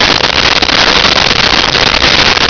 Sfx Amb Steam Loop
sfx_amb_steam_loop.wav